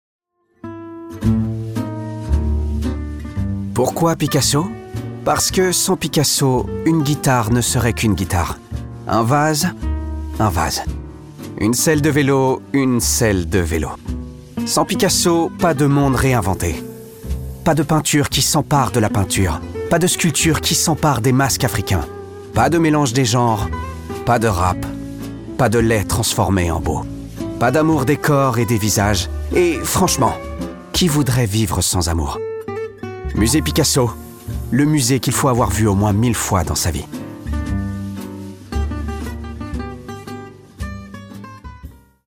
Pub Musée Picasso